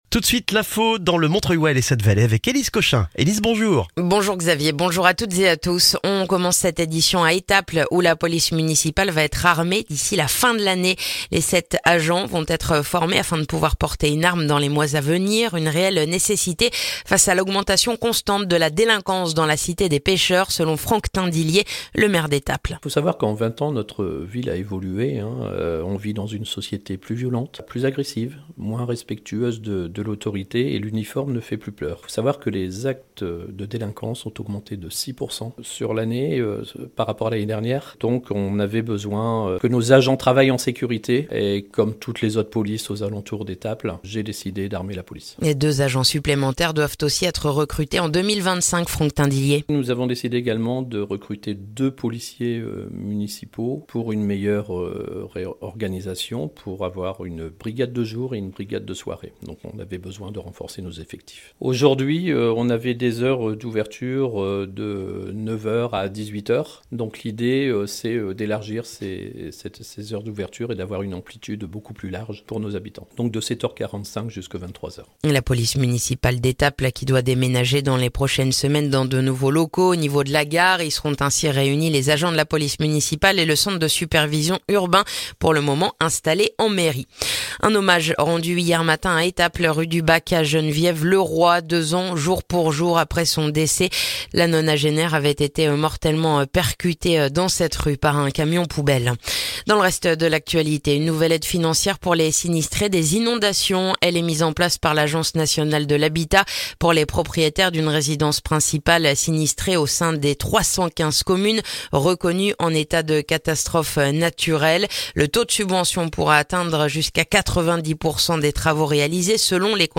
Le journal du mercredi 19 juin dans le montreuillois